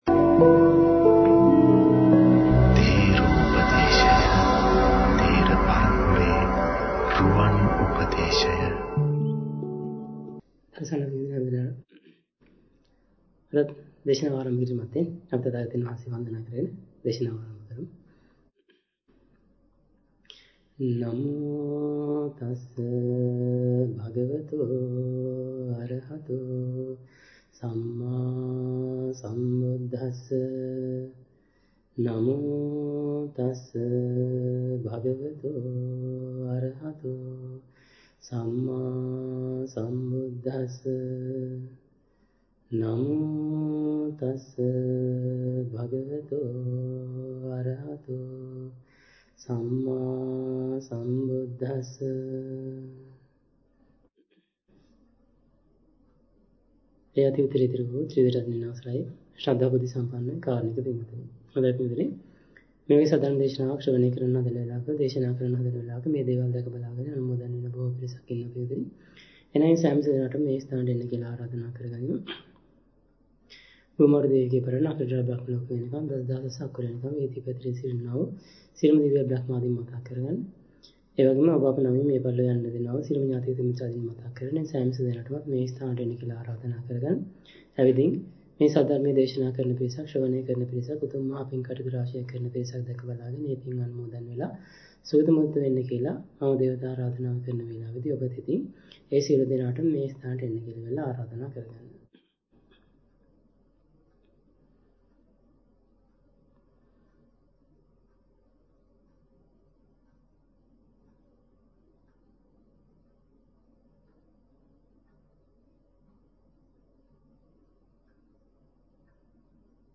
Sermon | JETHAVANARAMA